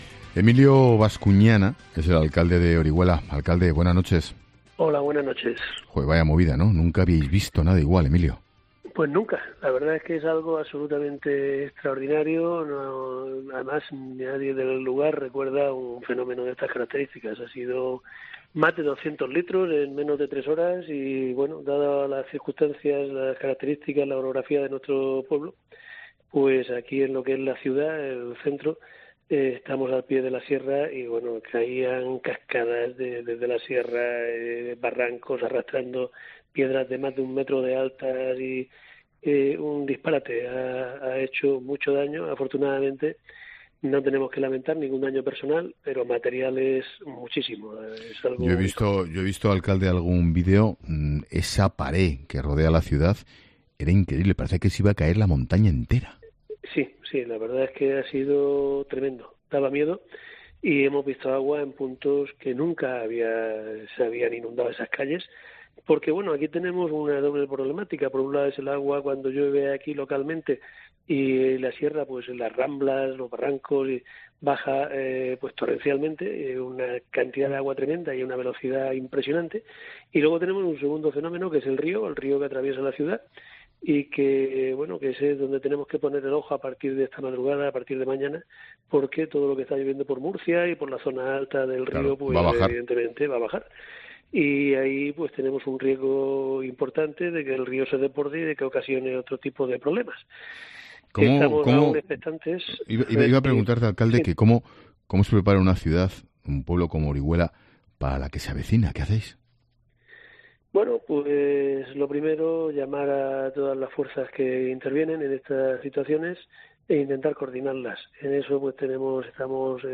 En 'La Linterna', con Ángel Expósito, ha estado el alcalde de Orihuela, Emilio Bascuñana, quien ha informado sobre los destrozos que está causando la gota fría en la ciudad.